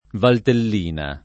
vai all'elenco alfabetico delle voci ingrandisci il carattere 100% rimpicciolisci il carattere stampa invia tramite posta elettronica codividi su Facebook Valtellina [ valtell & na ] (antiq. Val Tellina [ id. ]) top. f. (Lomb.)